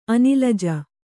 ♪ anilaja